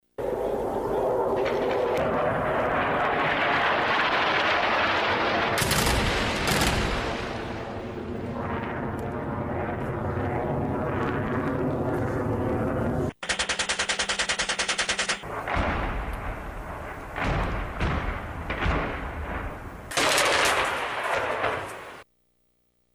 Exercițiul, bazat pe un scenariu de apărare colectivă, a inclus focuri de aviație și artilerie desfășurate în sprijinul unităților blindate terestre, care apărau zona împotriva unei amenințări:
insert-foc-Cincu.mp3